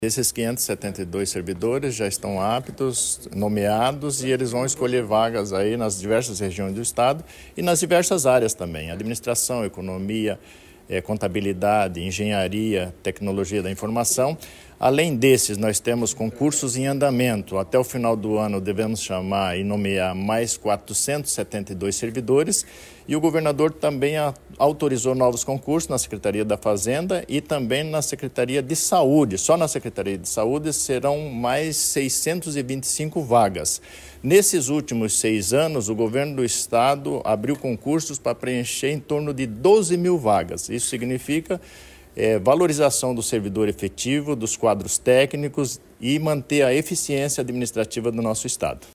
Sonora do secretário de Administração e da Previdência, Luizão Goulart, sobre a nomeação de 572 novos servidores para reforçar o quadro do Poder Executivo